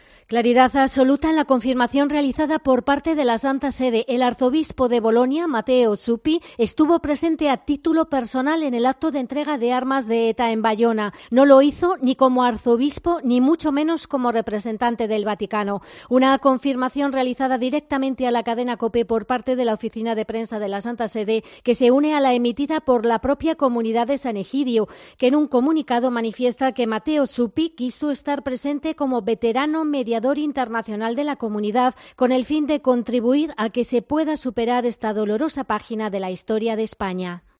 Comunicado de la Santa Sede.